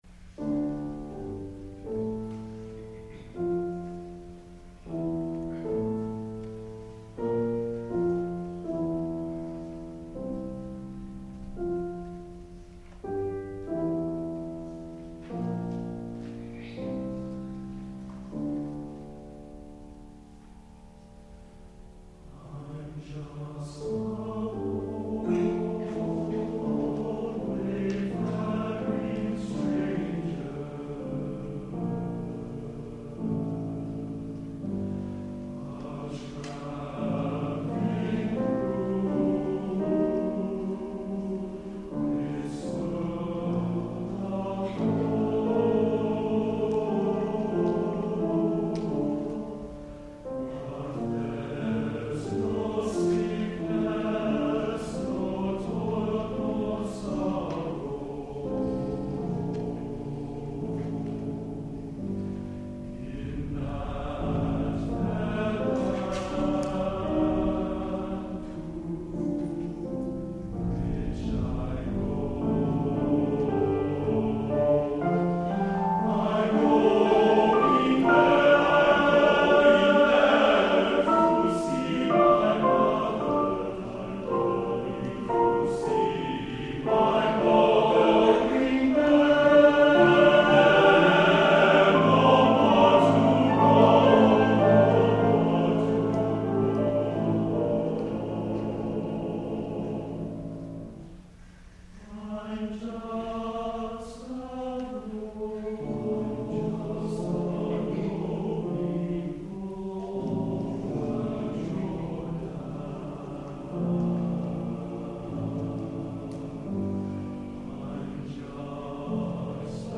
TBB (3 voix égales d'hommes).
Instrumentation : Piano